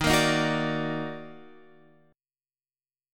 D#mM7 chord